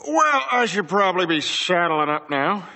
Worms speechbanks
Boring.wav